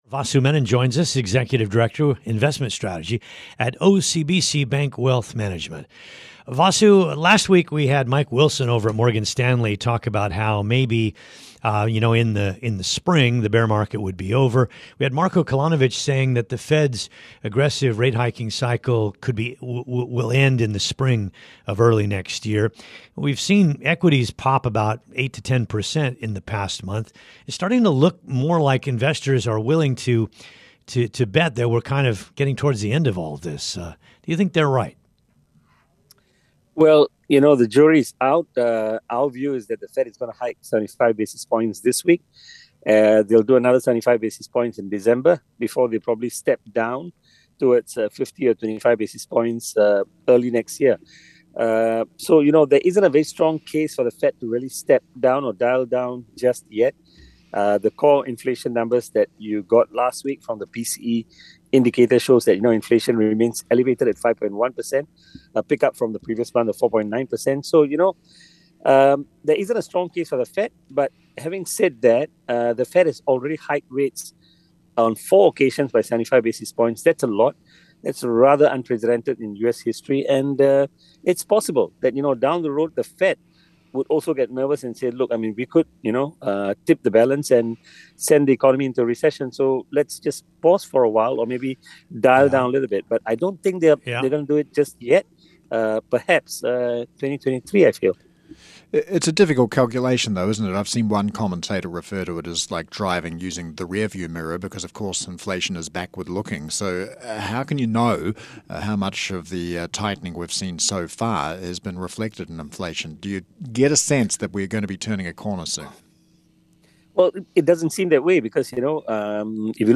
(Radio)